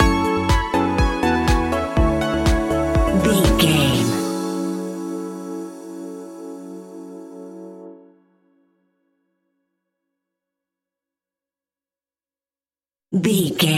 Tropical Dance Stinger.
Aeolian/Minor
F#
groovy
happy
piano
drum machine
synthesiser
house
electro house
synth leads
synth bass